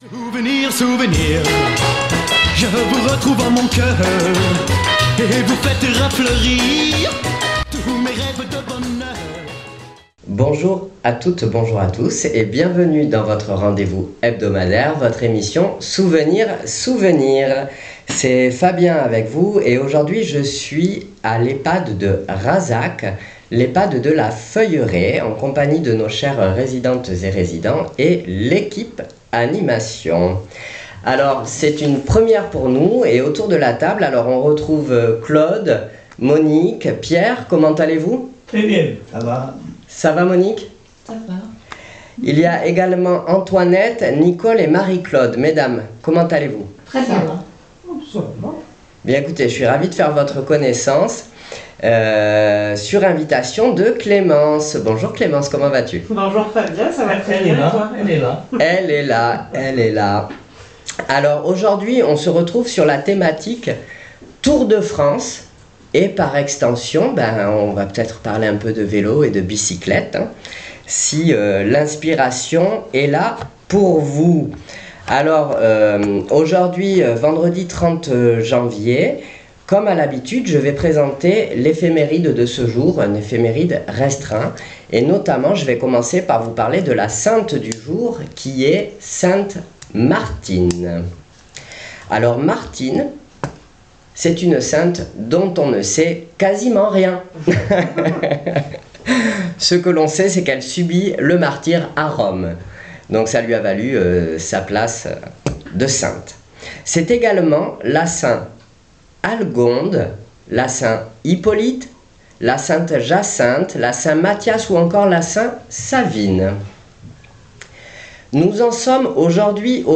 Souvenirs Souvenirs 30.01.26 à l'Ehpad de Razac " Le Tour de France "